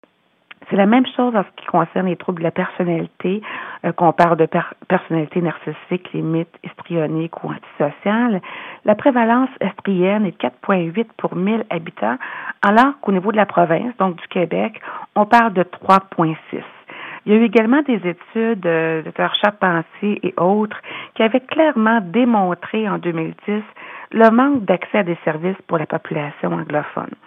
Hablando con ella por téléfono